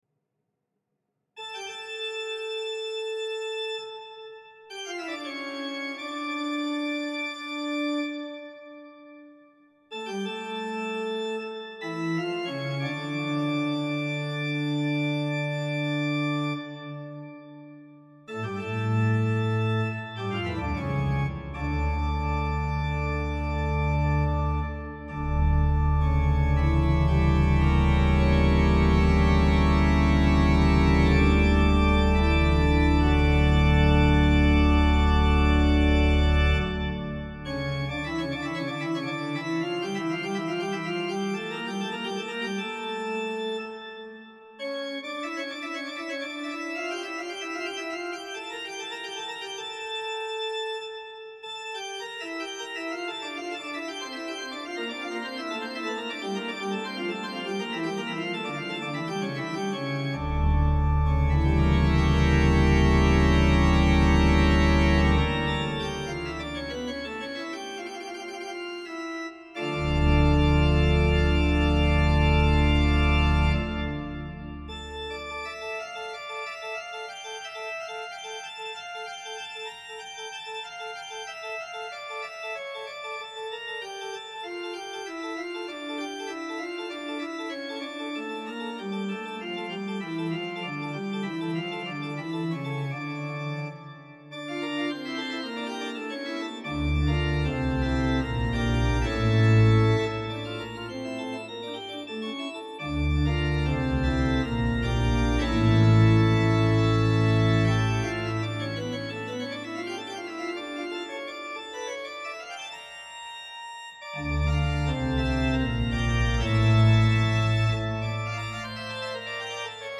No additional effect processing have been added to the recording. The tail of release is the original of the samples.
J.S.Bach - Toccata e Fuga in Re Minore Pedal: Subbasso 16 + Tappato 8 + Flauto 4
Great: Principale 8 + Ottava 4 + Quintadecima + Ripieno 1 1/3 + Flauto a Camino 8
Swell: Principale 4 + Cimbalo 1 + Bordone Vivace 8
Unions: Manual 1 to Pedal